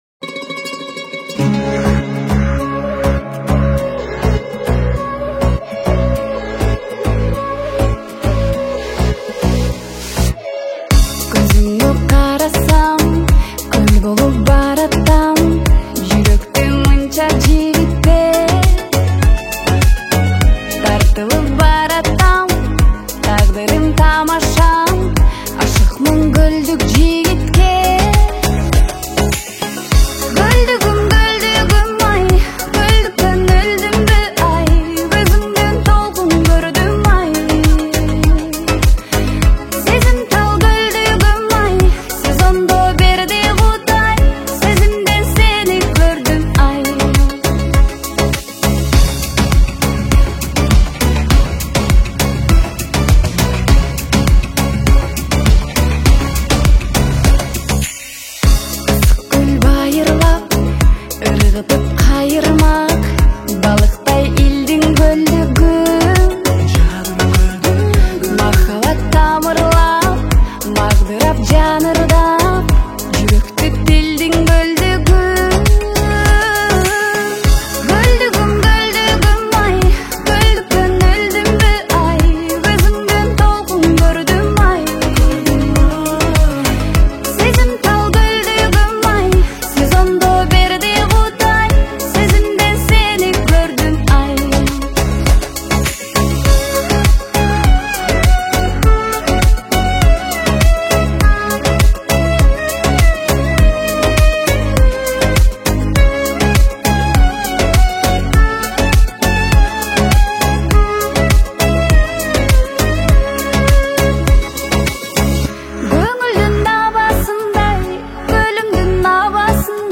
Казахская